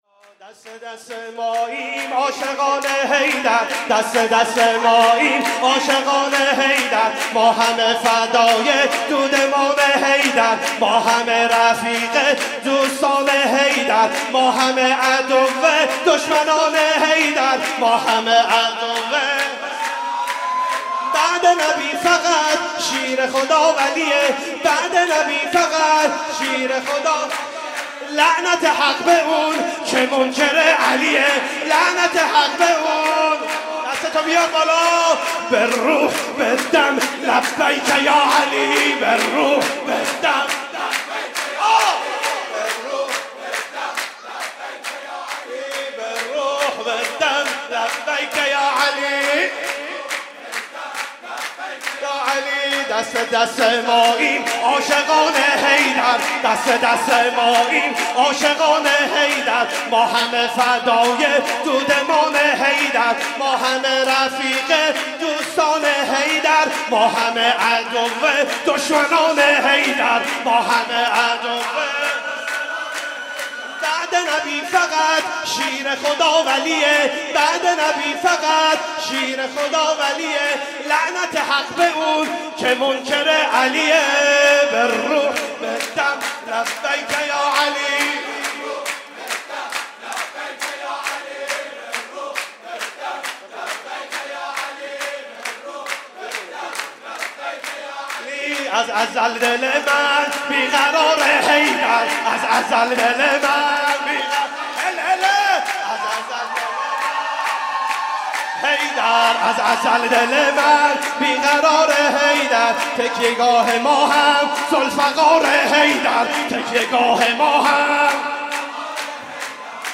ولادت امام علی (ع)
مداحی